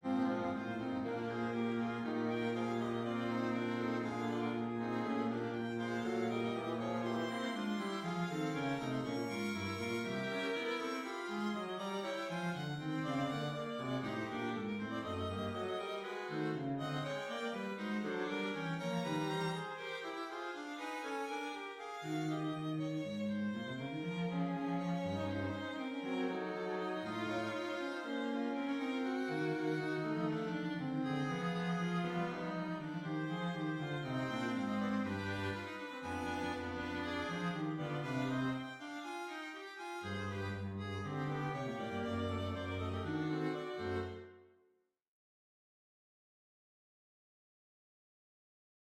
On entend ici plusieurs apparitions du sujet exposé de manière modifiée.
Ce passage modulant est d’ailleurs extrêmement surprenant.
ex-4-fugue-sujet-modulant-ascendant-audio.mp3